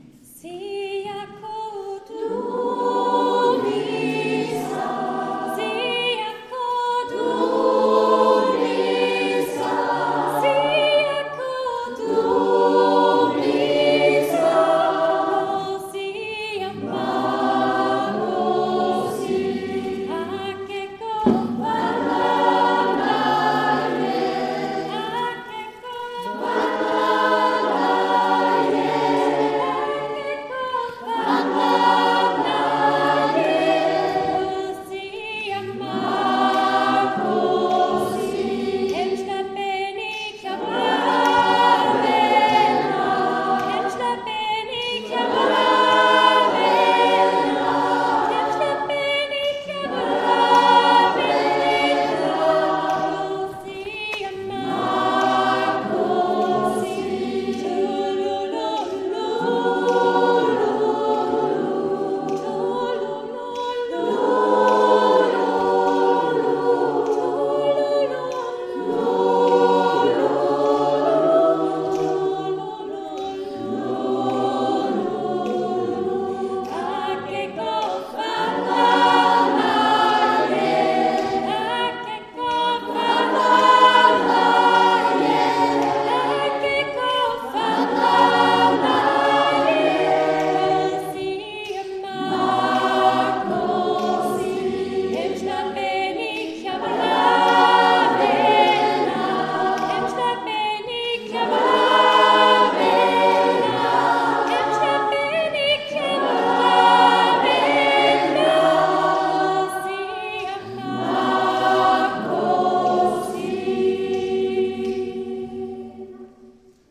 Die afrikanischen Lieder aus dem Gottesdienst